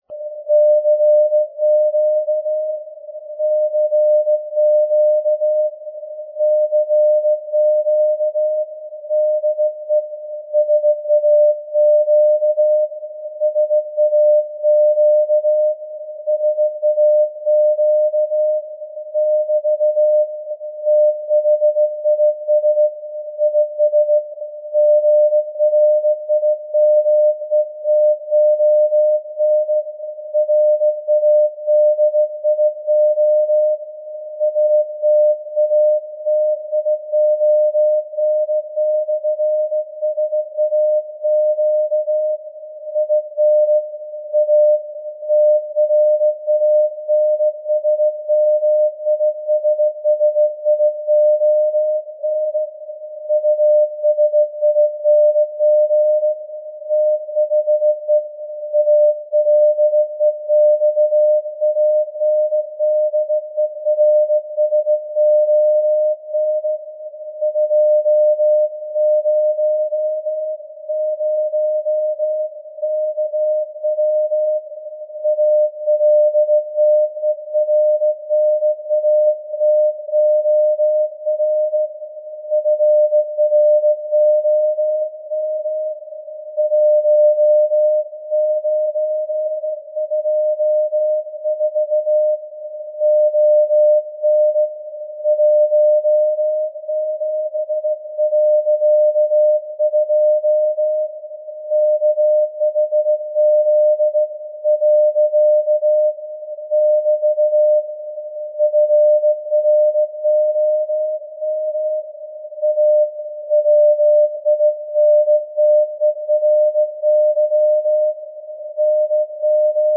This time too the SAQ reception was performed sampling directly at 17.2 kHz, according to this flow diagram :
This is the complete CW message as received from the SAQ This is the Alexanderson alternator, that directly generates the 17.2 kHz signal, with a power of 200 kW.